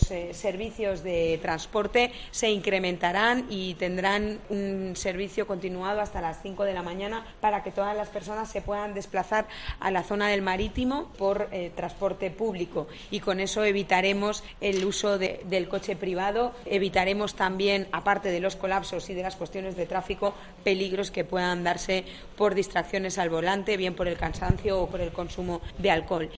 Declaraciones de Pilar Bernabé sobre el transporte público